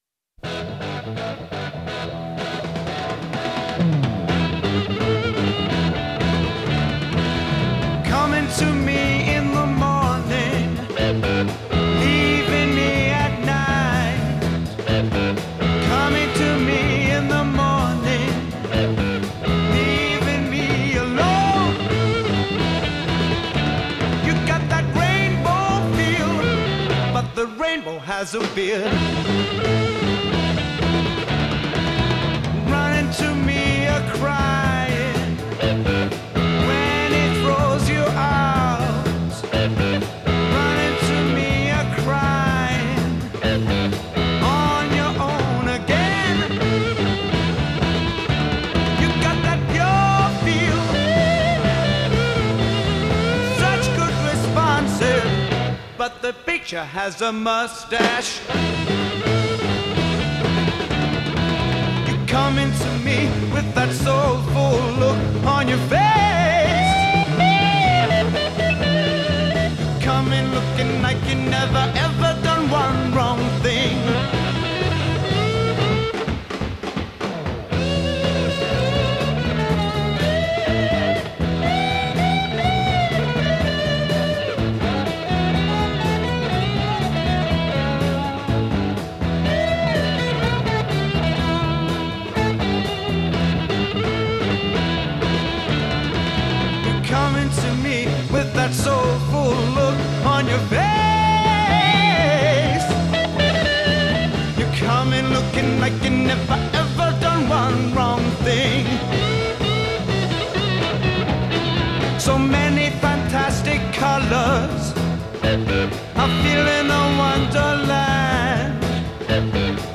the licks and riffs are beyond insanely good!